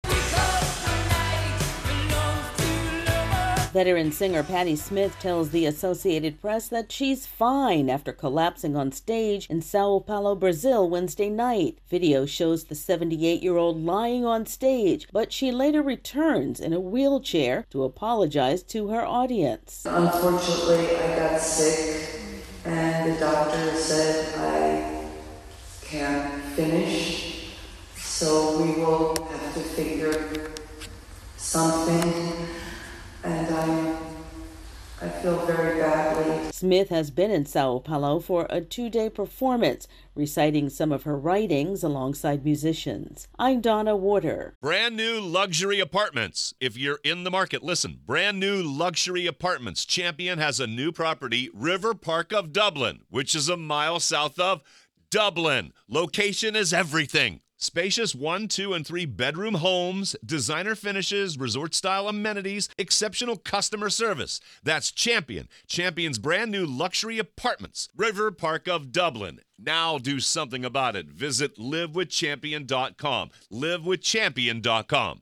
Singer Patti Smith says her collapse on stage in Brazil has been "grossly exaggerated." AP correspondent